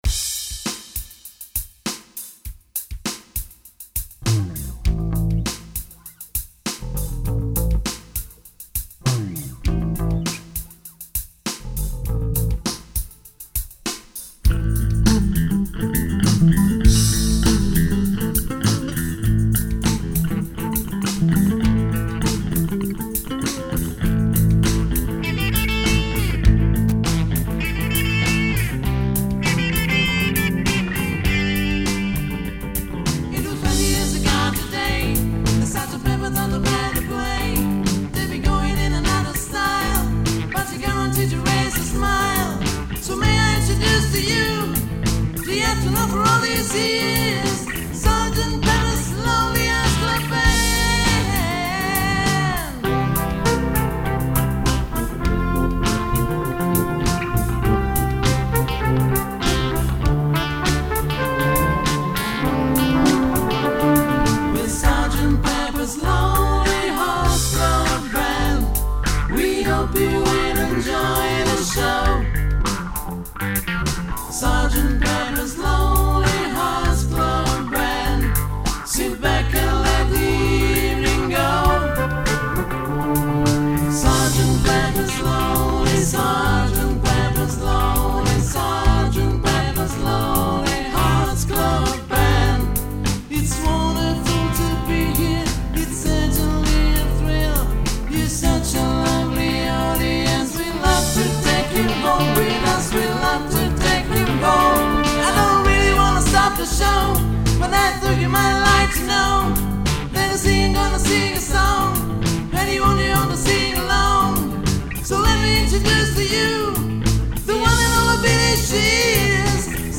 chitarra, piano, basso, armonica, voce
basso, ukulele, chitarra, voce
tastiere, voce
batteria, percussioni, voce